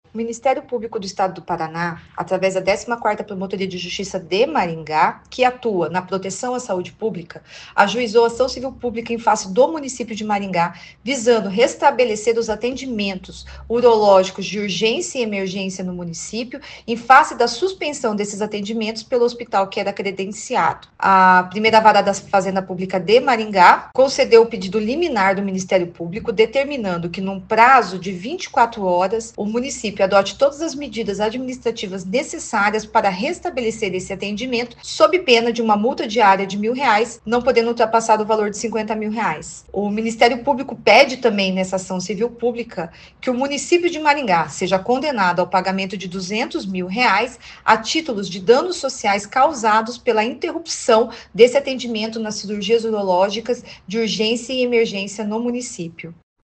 Ouça o que disse a promotora de Justiça Michele Nader.